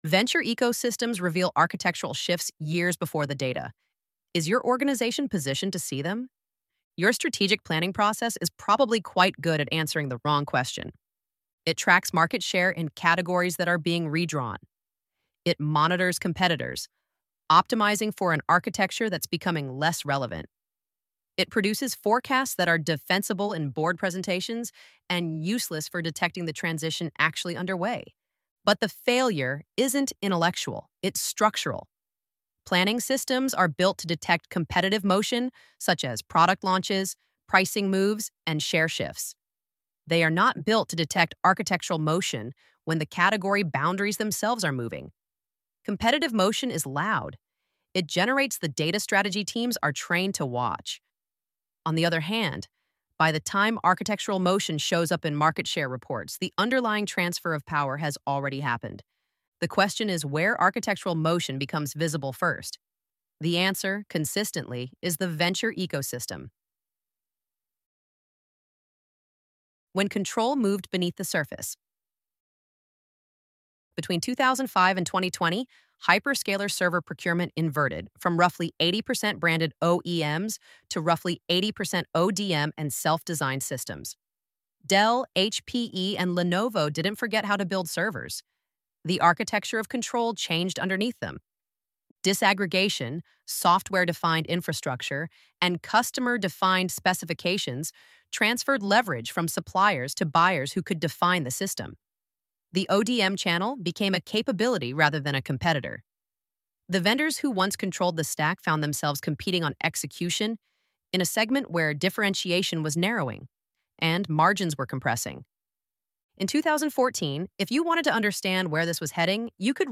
ElevenLabs_Why_Leaders_Miss_Architectural_Shifts_in_AI_Infrastructure.mp3